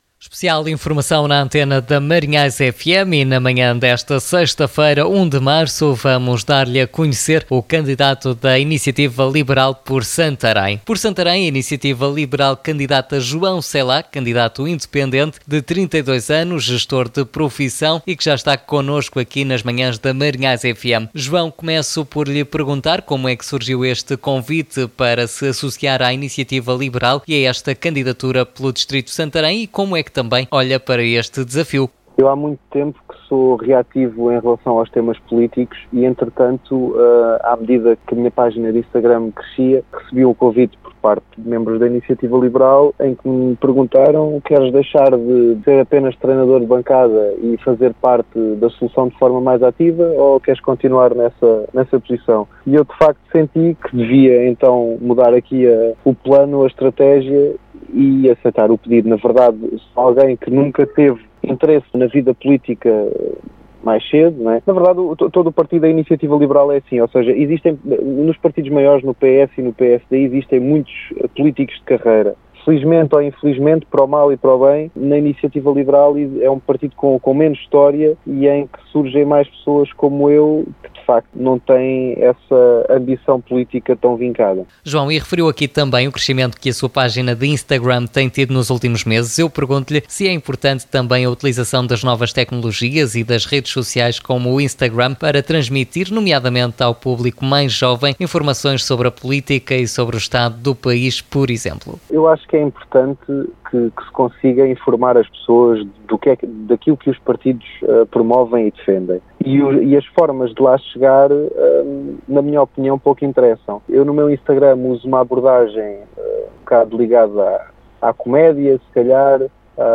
Uma entrevista que pode escutar, novamente, na íntegra, aqui: